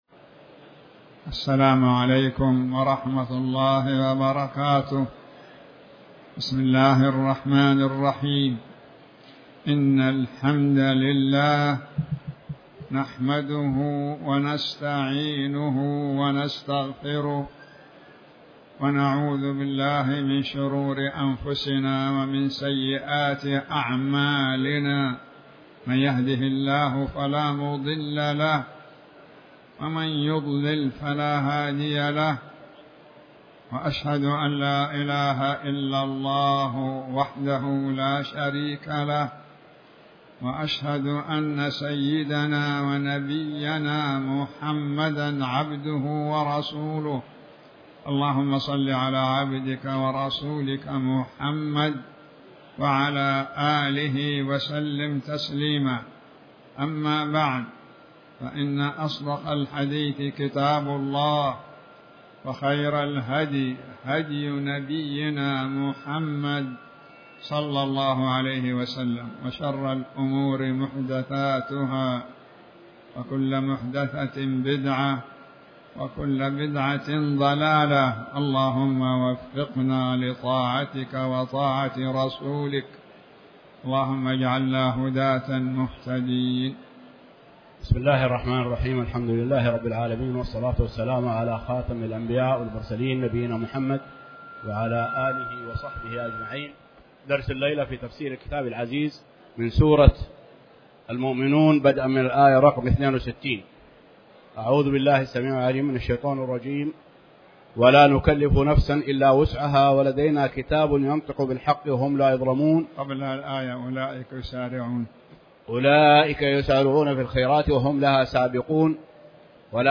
تاريخ النشر ٩ محرم ١٤٤٠ هـ المكان: المسجد الحرام الشيخ